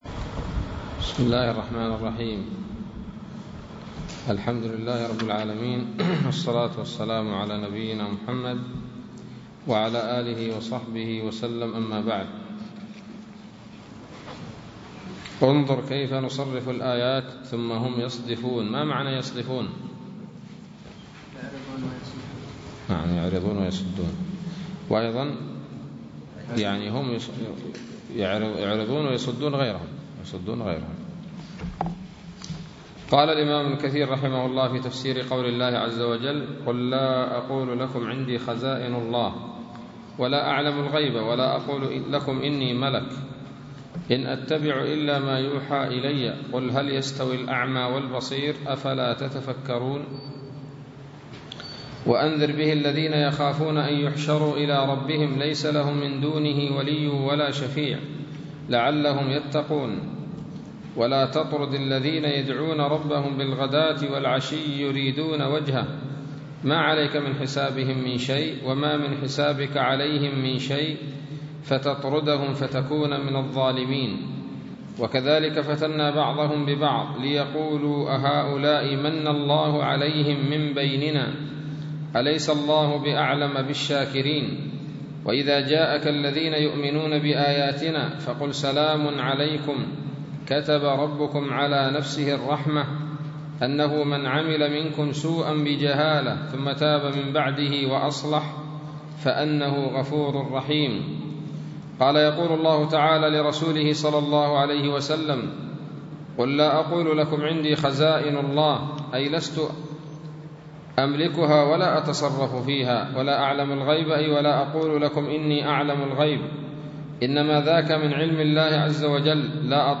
الدرس الحادي عشر من سورة الأنعام من تفسير ابن كثير رحمه الله تعالى